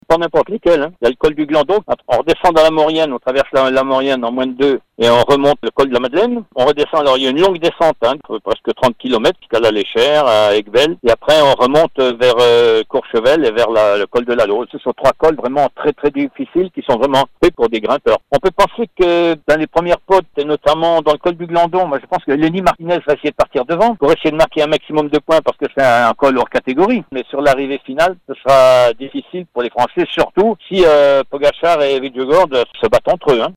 Bernard Thévenet, ancien double vainqueur de la grande-boucle revient sur les 3 difficultés de la 18ème étape entre Vif et Courchevel.